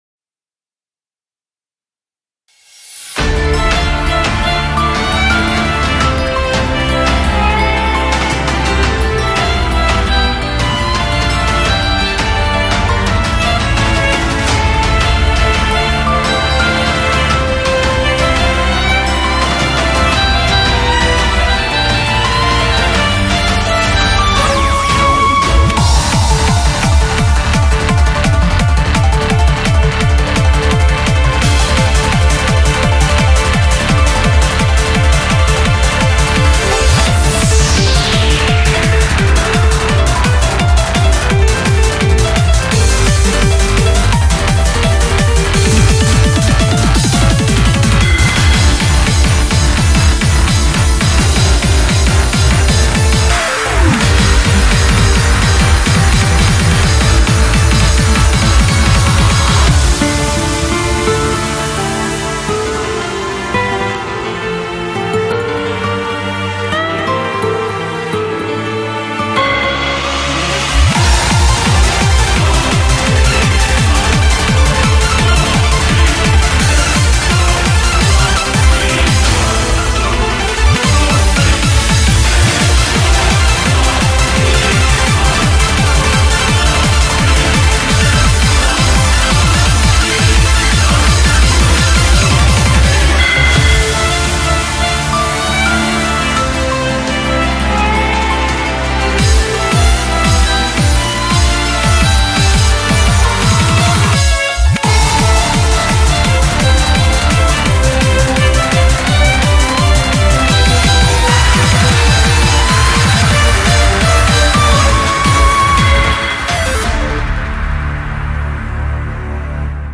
BPM85-170